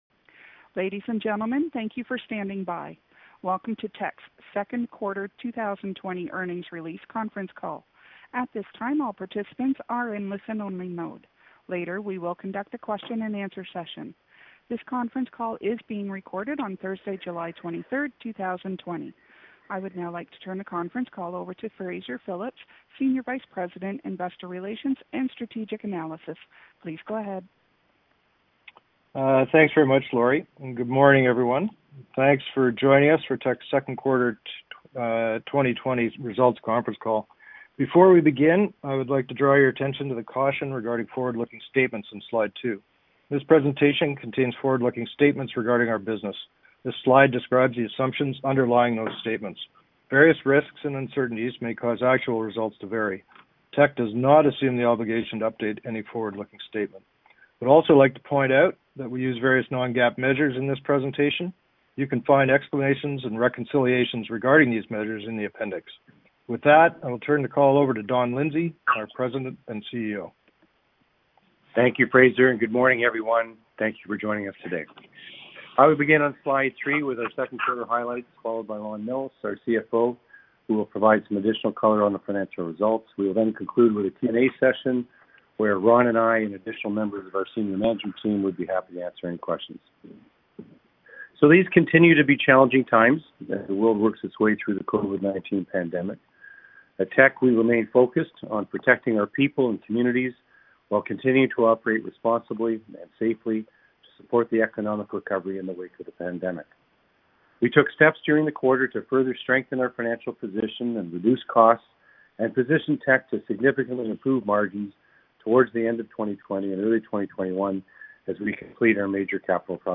Q2 2020 Financial Report Conference Call Audio